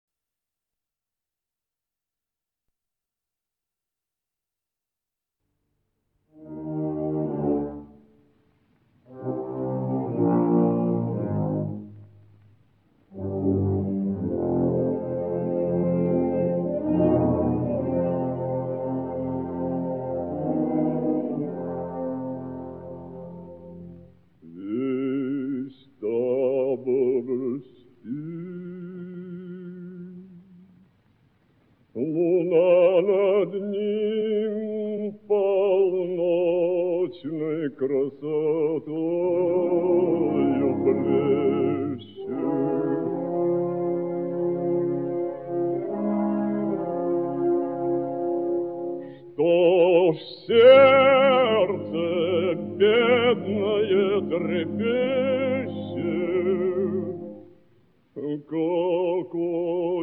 Одноактная опера на либретто В. И. Немировича-Данченко по поэме А. С. Пушкина «Цыганы»
Хор и оркестр Всесоюзного радио, дирижёр Н. С. Голованов.